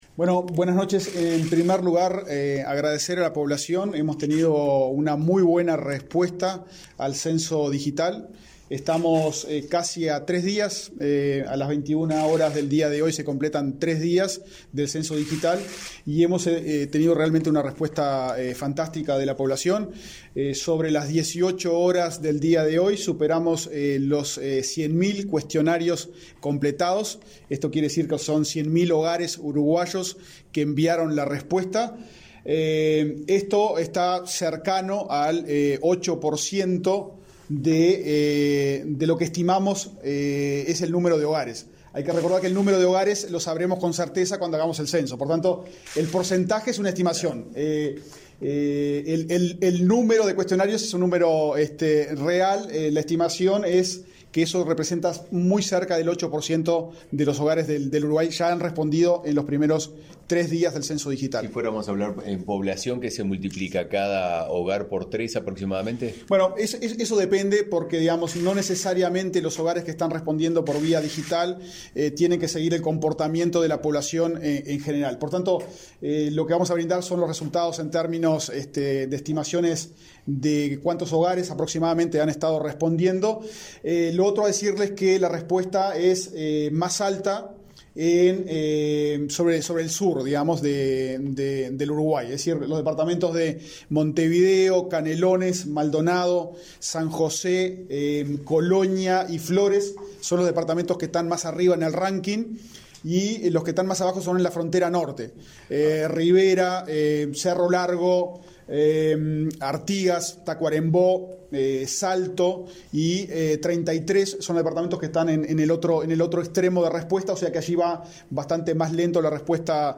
Declaraciones a la prensa del director técnico del INE, Diego Aboal